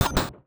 UIClick_Menu Double Mallet Metal Hollow 02.wav